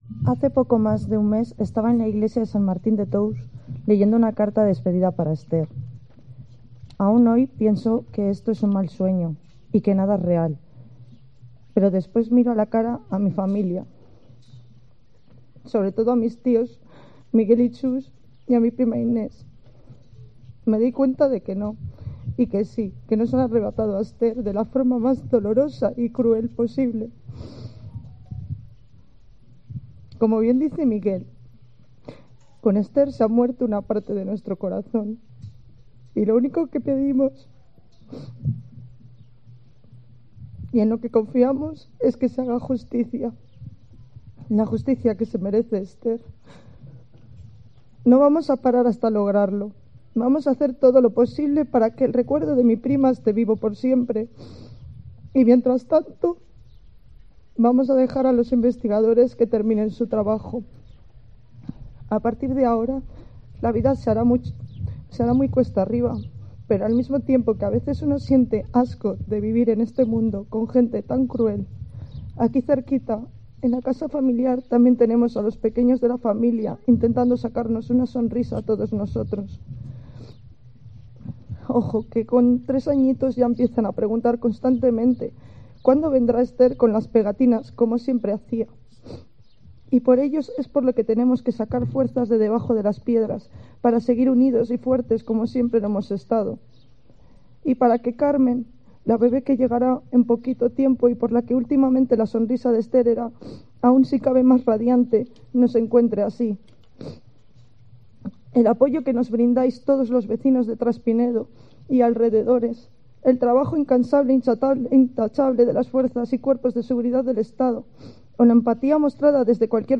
El aplauso emocionado de los vecinos ha roto el silencio en el que se ha sumido la localidad vallisoletana durante el homenaje al cumplirse dos meses de su desaparición